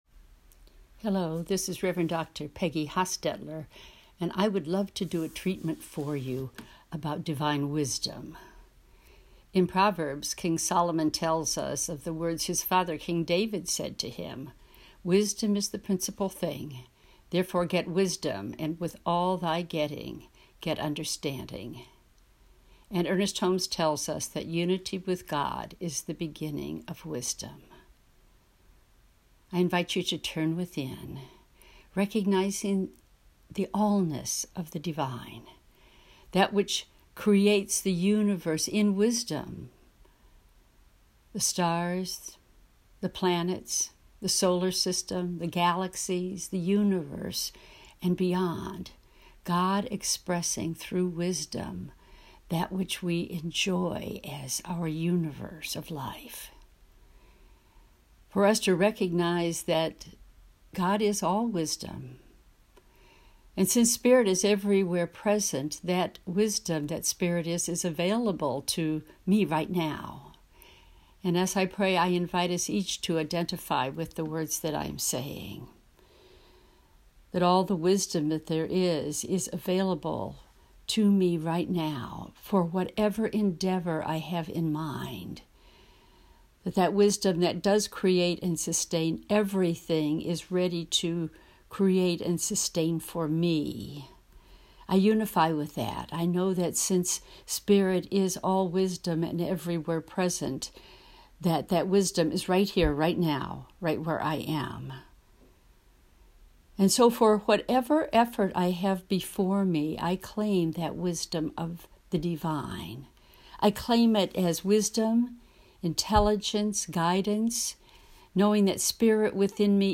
Recorded Prayers